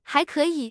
tts_result_8.wav